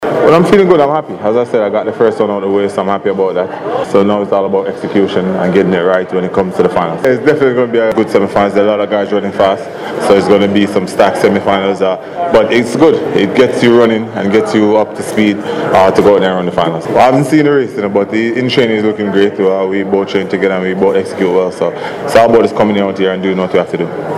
Usain Bolt verseny után azt mondta:
Hallgassa meg Usain Bolt nyilatkozatát angolul: